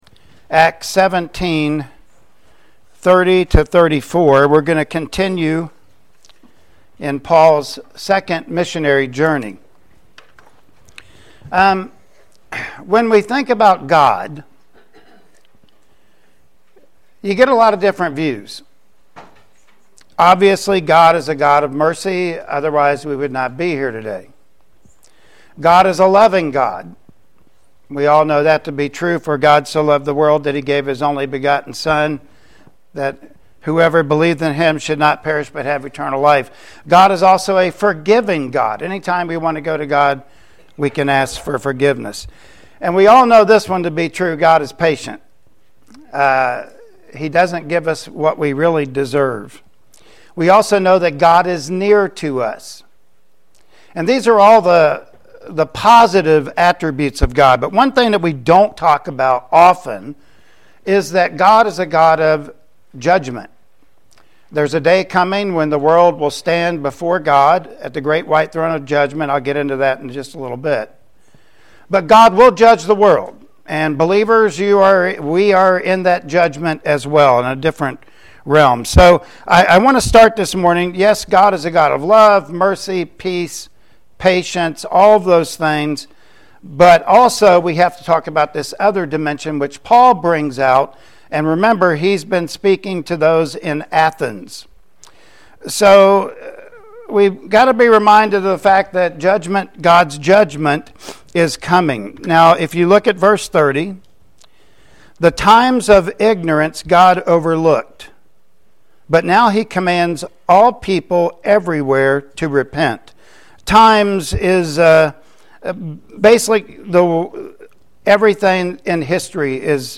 Acts 17:30-34 Service Type: Sunday Morning Worship Service Topics: Judment & Scoffers